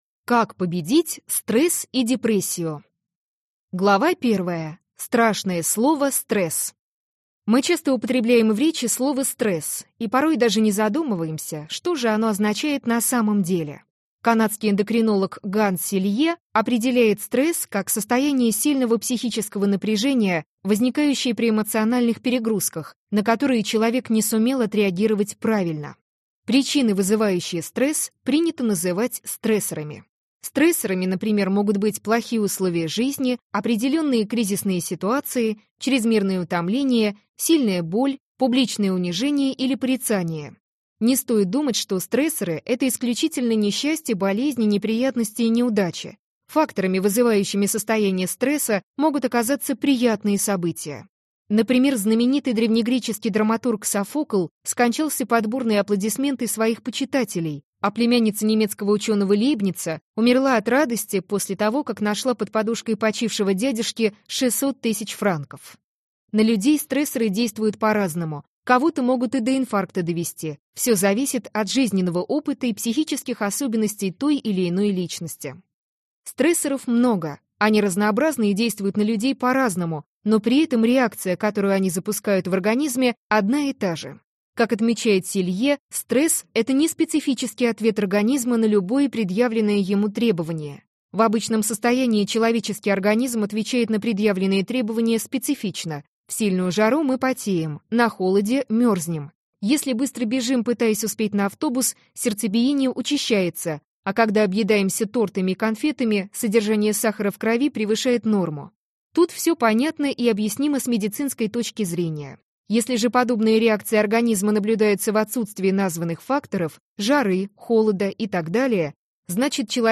Аудиокнига Самоучитель легкой жизни: секреты счастливых | Библиотека аудиокниг
Прослушать и бесплатно скачать фрагмент аудиокниги